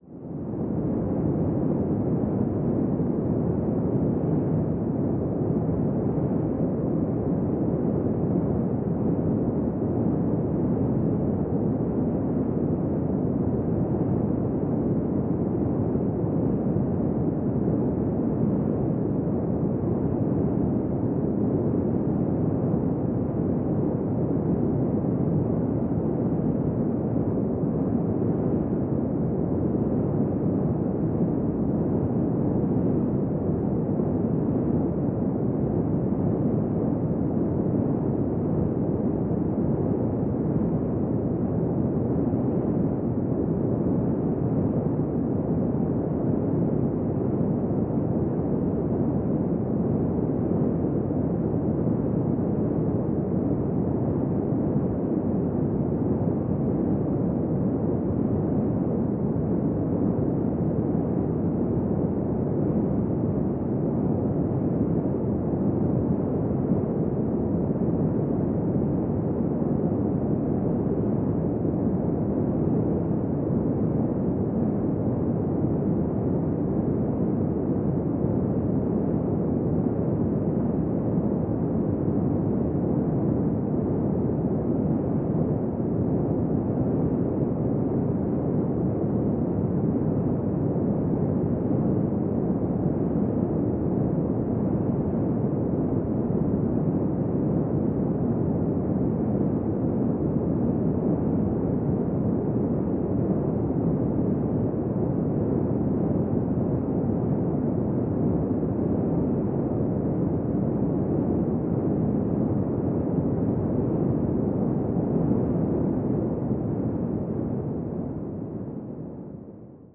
Windy Desert.mp3